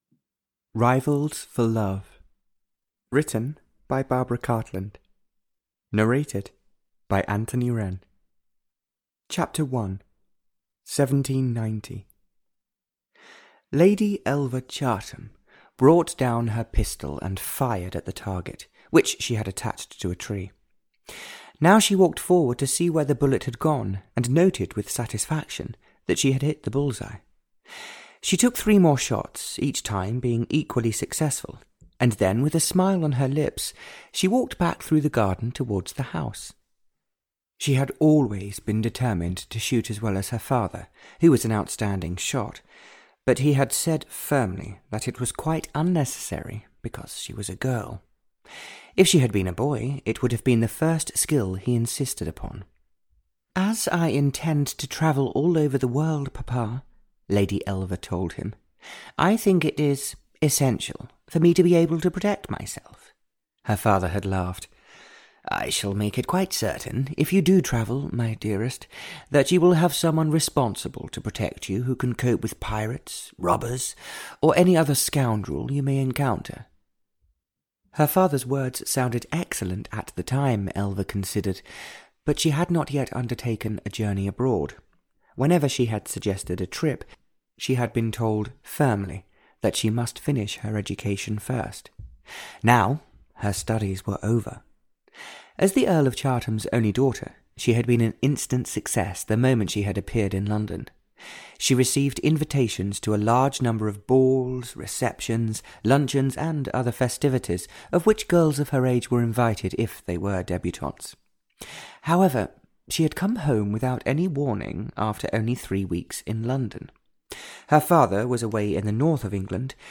Audio knihaRivals for Love (Barbara Cartland’s Pink Collection 47) (EN)
Ukázka z knihy